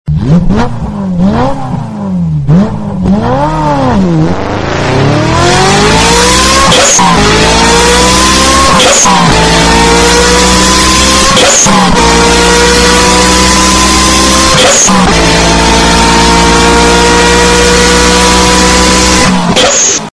Звуки автомойки